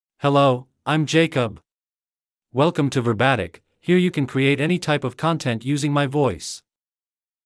Jacob — Male English (United States) AI Voice | TTS, Voice Cloning & Video | Verbatik AI
MaleEnglish (United States)
Jacob is a male AI voice for English (United States).
Voice sample
Listen to Jacob's male English voice.
Male
Jacob delivers clear pronunciation with authentic United States English intonation, making your content sound professionally produced.